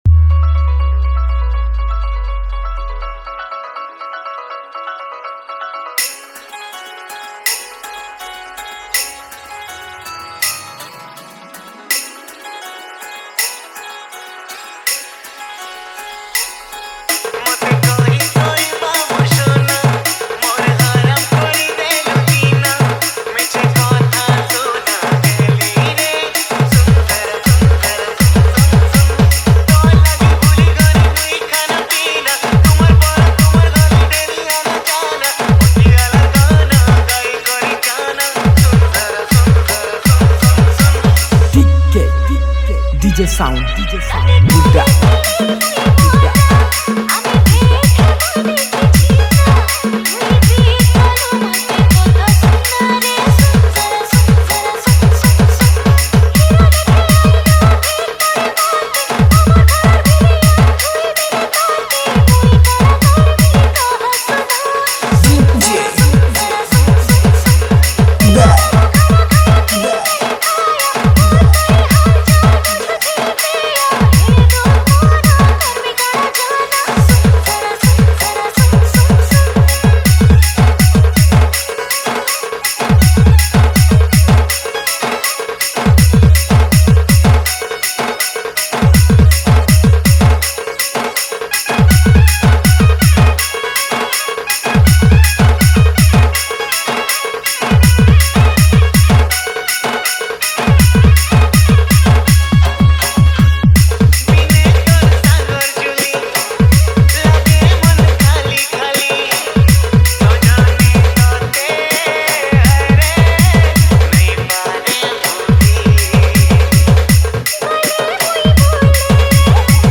Category:  New Sambalpuri Dj Song 2023